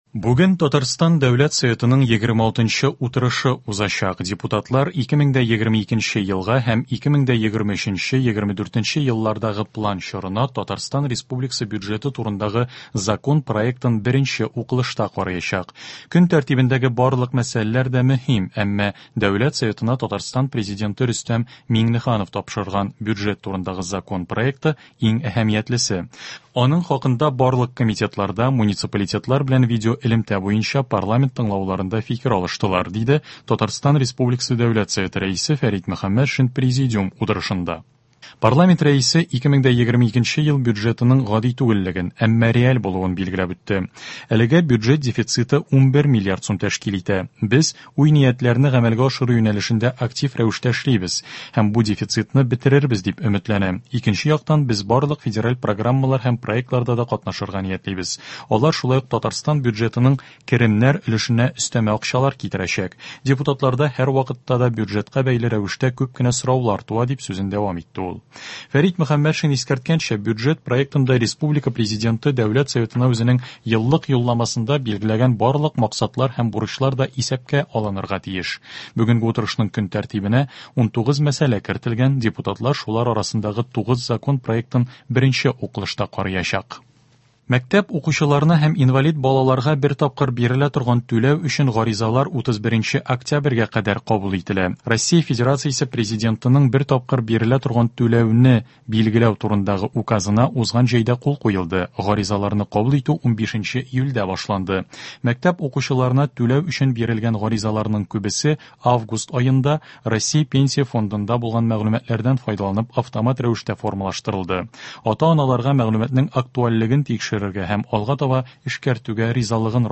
Яңалыклар (25.10.21)